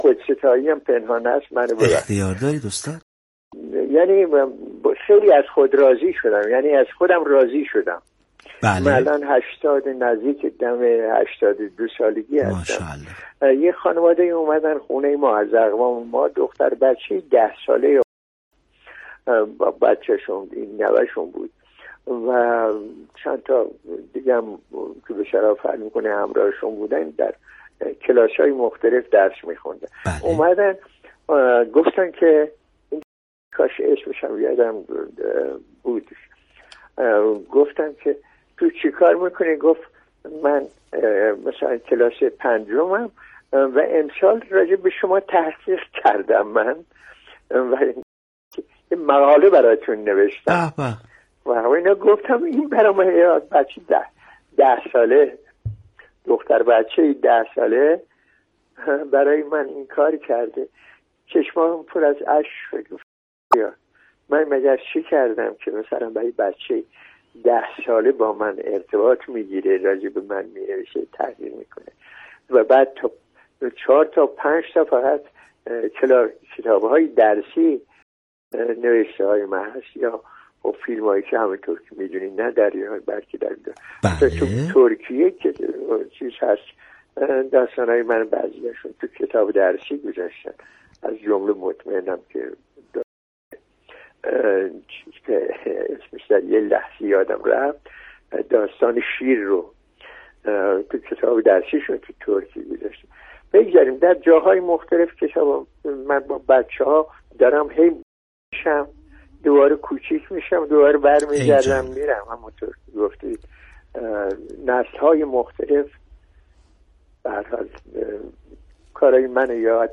ایکنا پای سخنان هوشنگ مرادی‌کرمانی